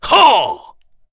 Dakaris-Vox_Attack5_kr.wav